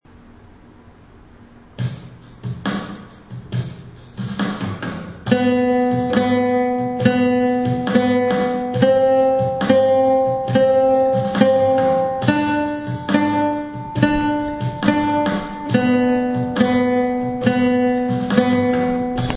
تمرینی با استفاده از نت های (سی.دو.ر)